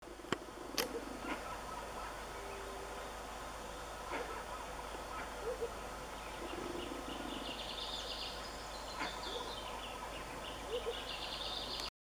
Burgo (Momotus momota)
Nombre en inglés: Amazonian Motmot
Fase de la vida: Adulto
Localidad o área protegida: Parque Nacional Calilegua
Condición: Silvestre
Certeza: Fotografiada, Vocalización Grabada